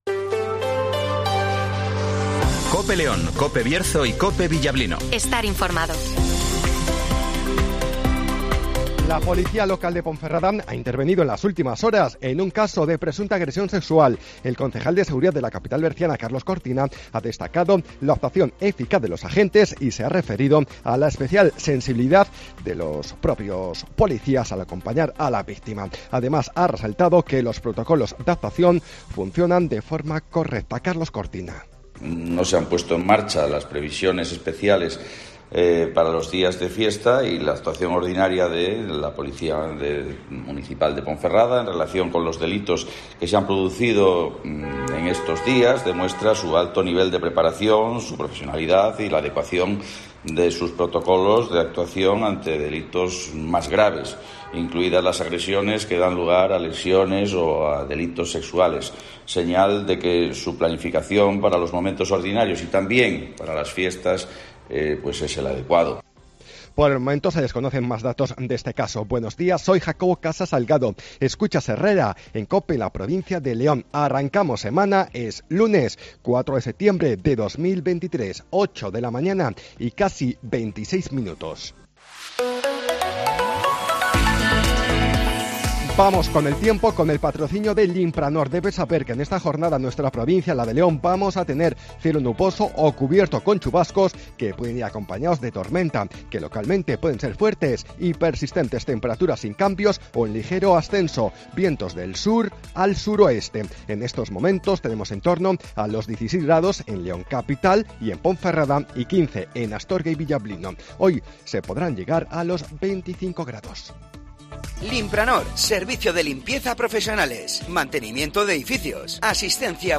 - Informativo Matinal 08:24 h